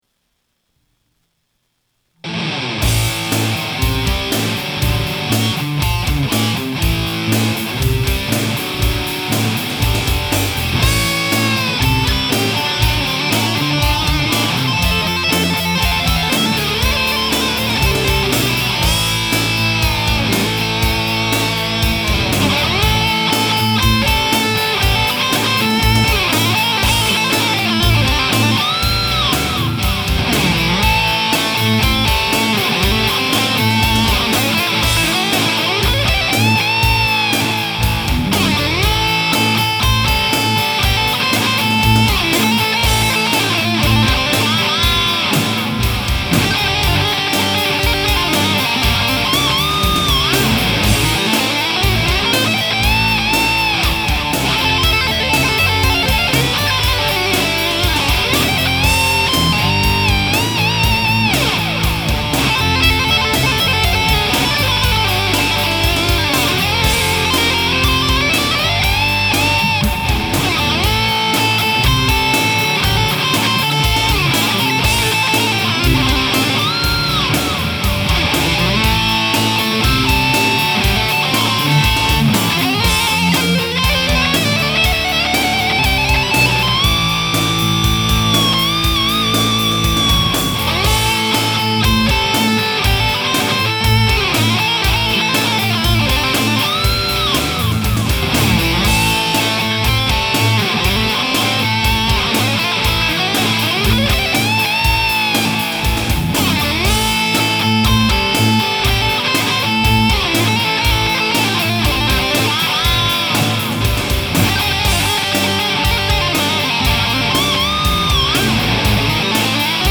• Drums – Boss, Dr. Rhythm Drum Machine
• Recorded at the Park Springs Recording Studio